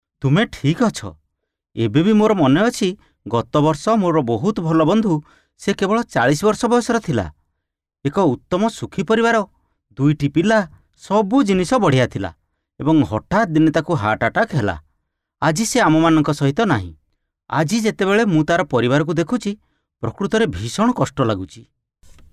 Oriya Voice Over Sample
Oriya Voice Over Male Artist 2